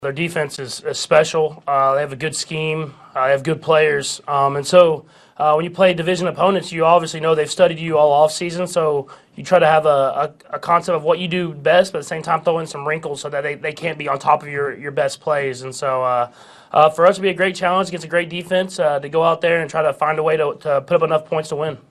Chiefs quarterback Patrick Mahomes says they cannot take the Broncos for granted.